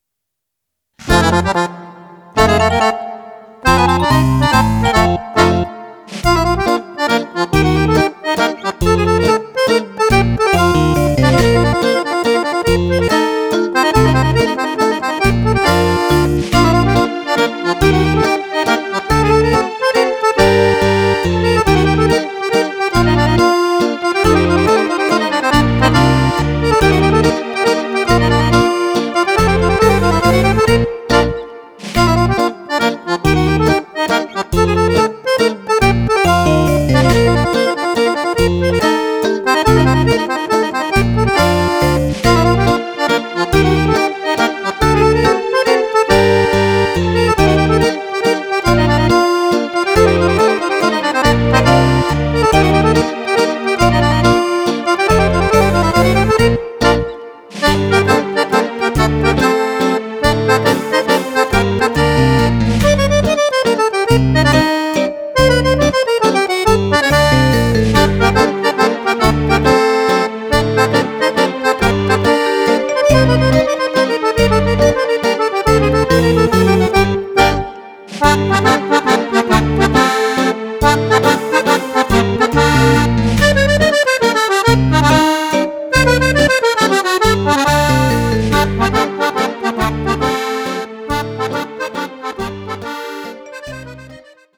Mazurka
10 Ballabili per Fisarmonica